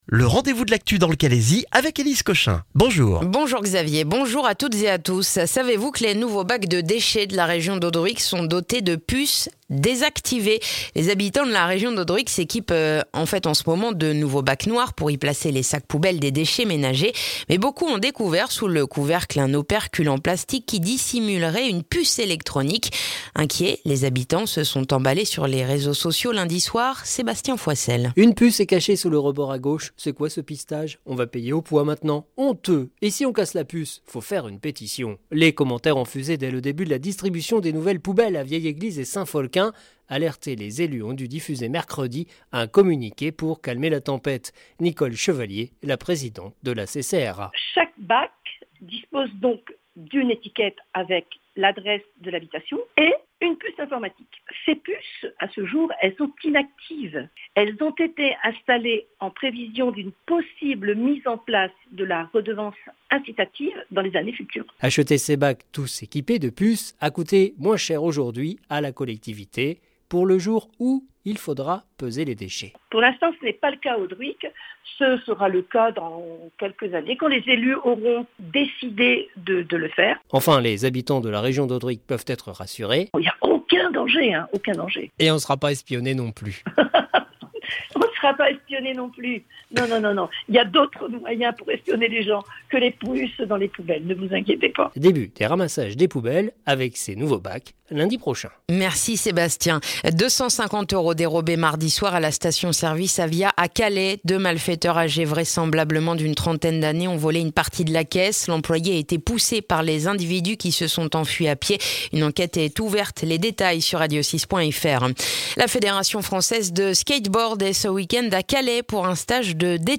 Le journal du jeudi 24 mars dans le calaisis